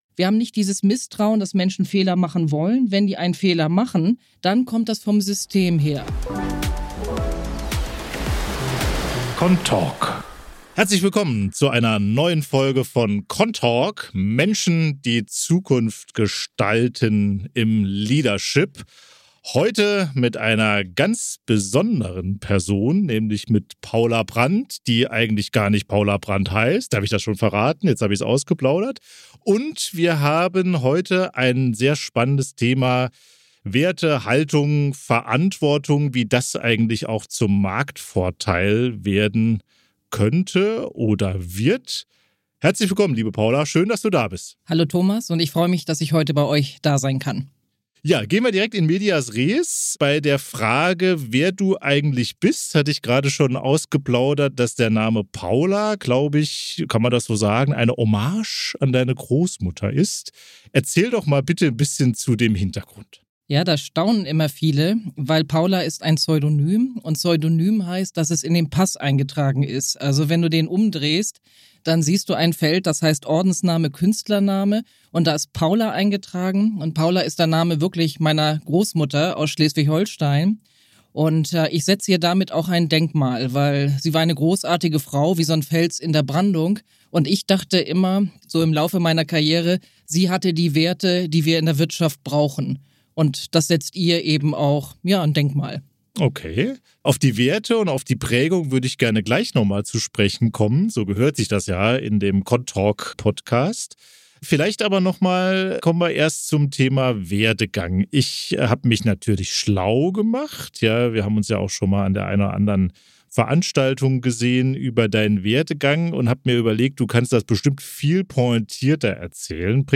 Ein Gespräch über Sinn, Systemmut, echte Führung und darüber, warum die Zukunft besser sein kann als gedacht, wenn wir sie bewusst gestalten.